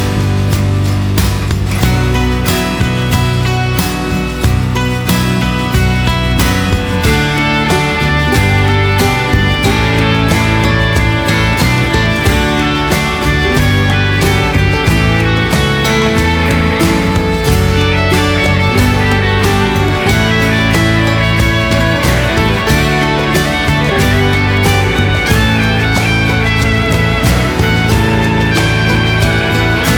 Жанр: Соундтрэки